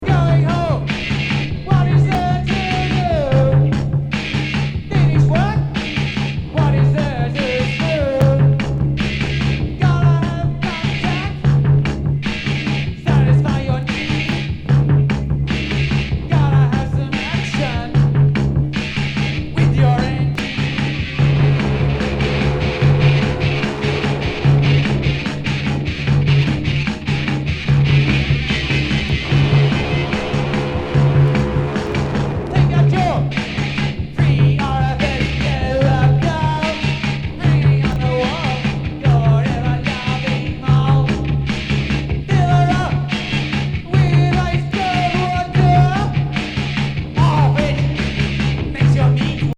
オージー・インダストリアル・レジェンド。